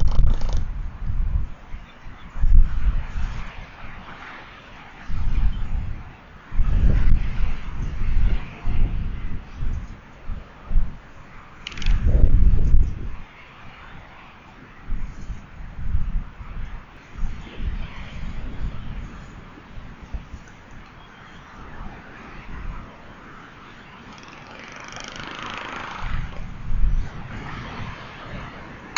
Diomedea exulans - Albatros errante
Movimientos de cabeza, chasquidos, alas abiertas y vocalizaciones forman parte del ritual de apareamiento.
Albatros errante.wav